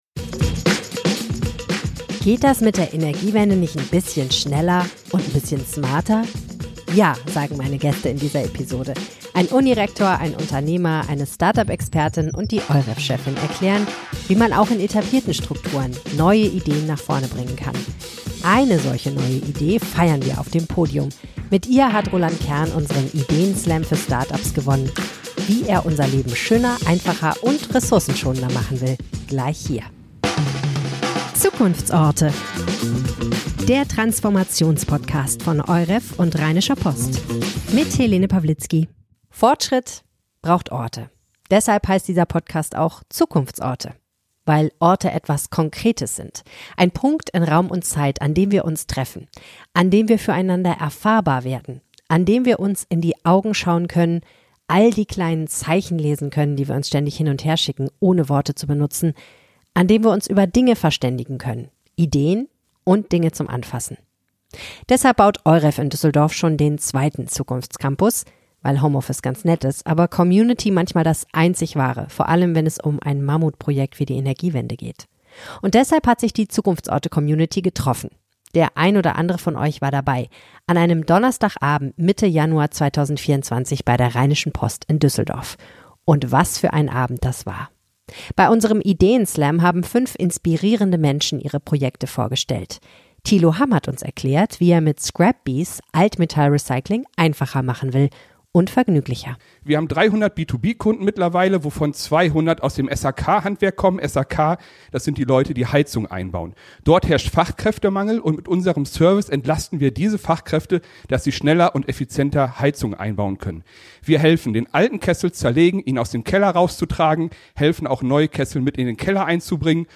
Wie bringen wir mehr Geschwindigkeit und Power in die Energiewende? Eine Live-Diskussion mit tollen Gästen.